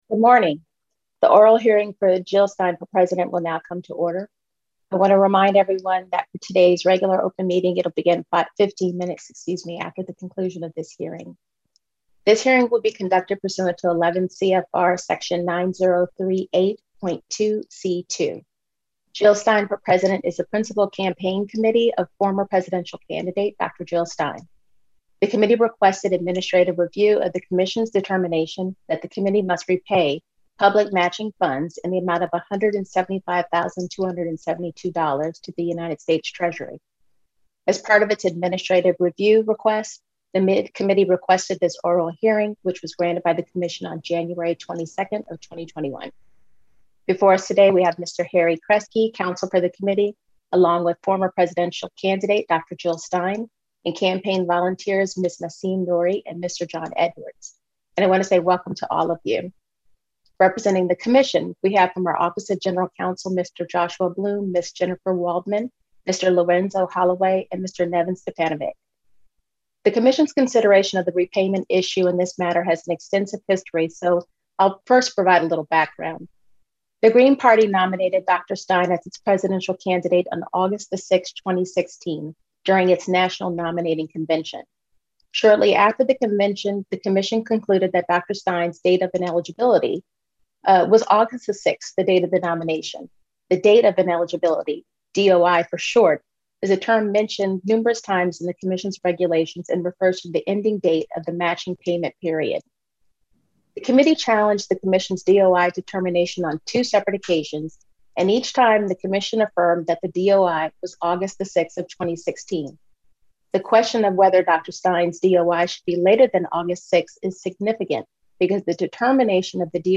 February 25, 2021 administrative review hearing